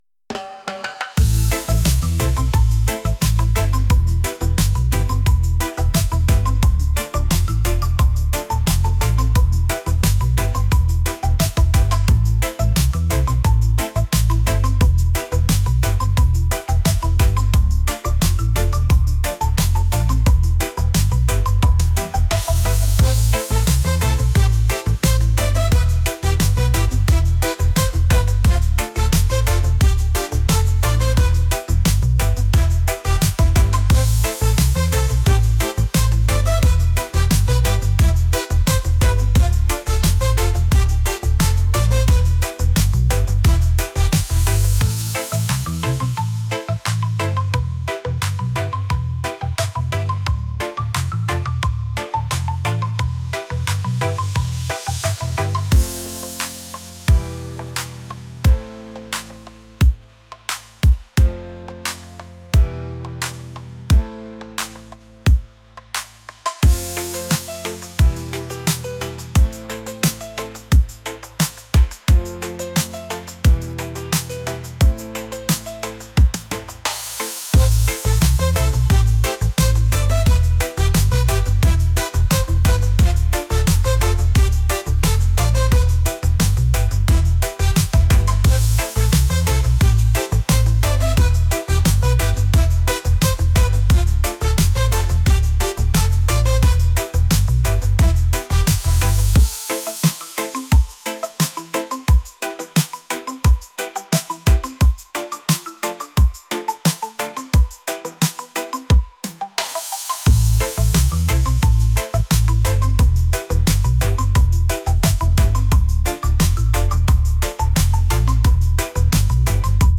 reggae | latin | electronic